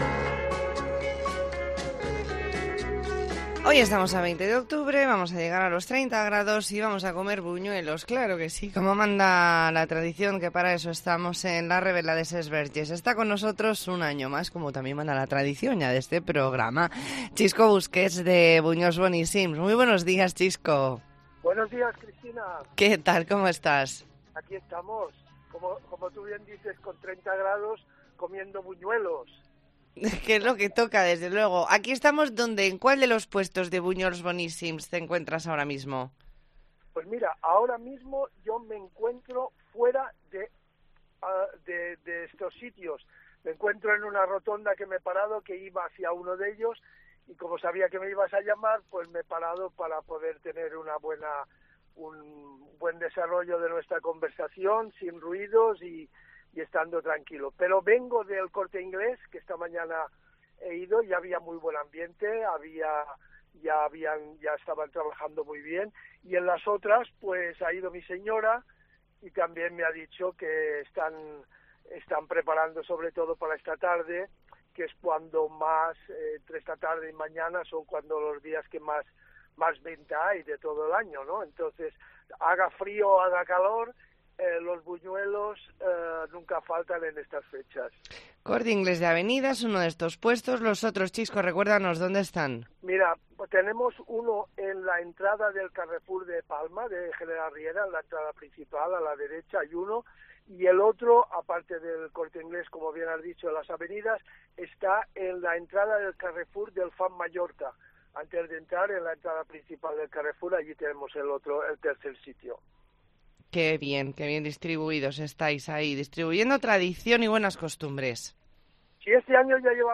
ntrevista en La Mañana en COPE Más Mallorca, jueves 20 de octubre de 2022.